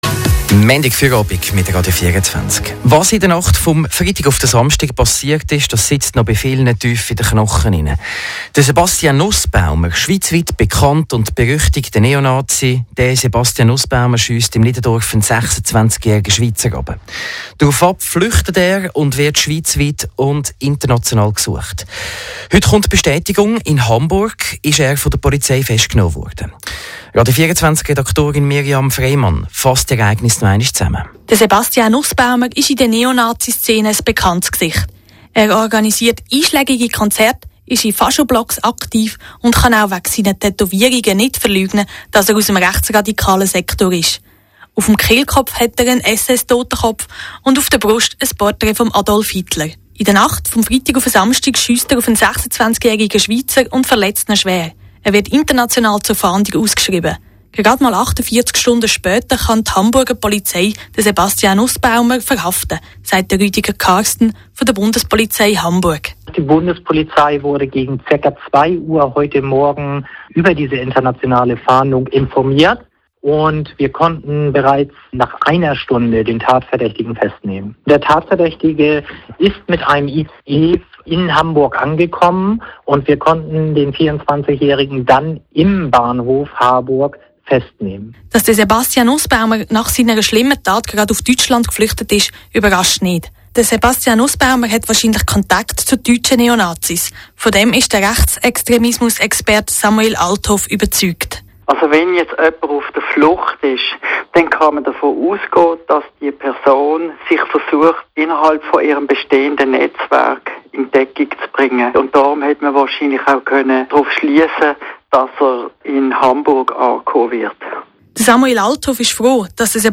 Beitrag von Radio 24